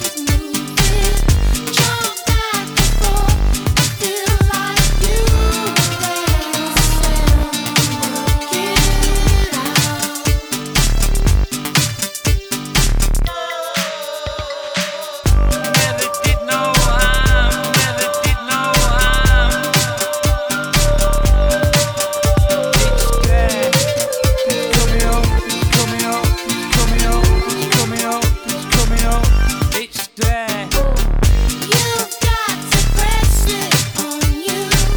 Жанр: Хип-Хоп / Рэп / Поп музыка / Рок / Альтернатива